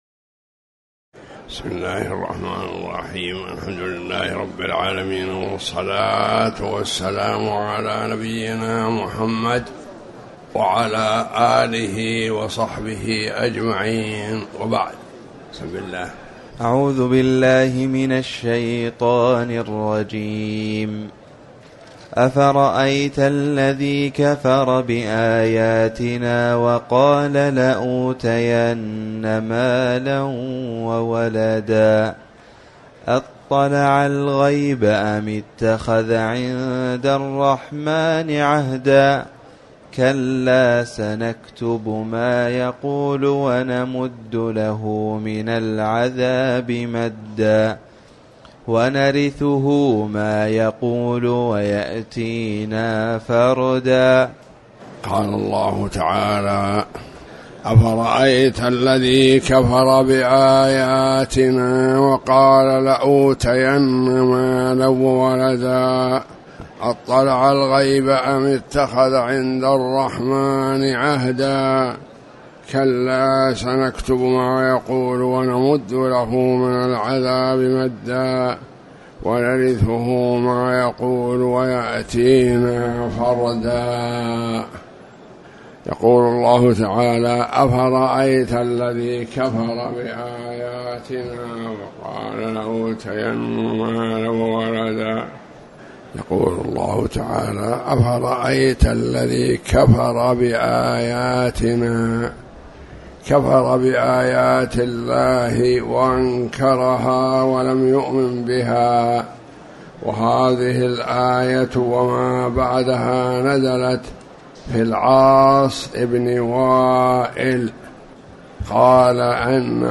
تاريخ النشر ١٩ ذو القعدة ١٤٣٩ هـ المكان: المسجد الحرام الشيخ